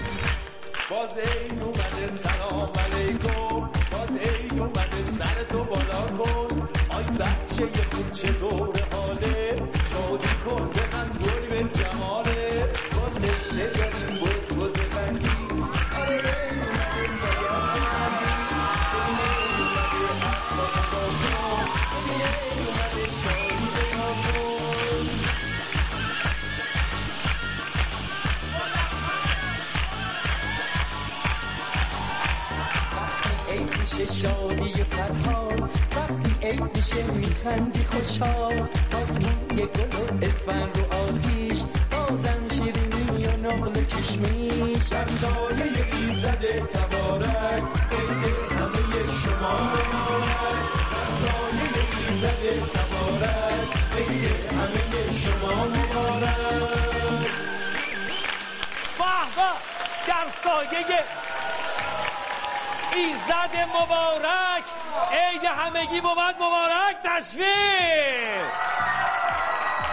دانلود آهنگ عید قربان ترکی با صدای بچه مناسب وضعیت واتساپ
سرود و شعر تبریک عید قربان با صدای بچه مناسب ساخت کلیپ